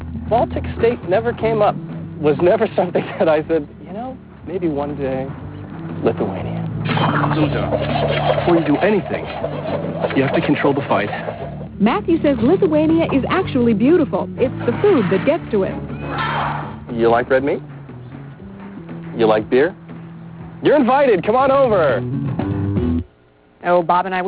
Here is a clip from an ET interview.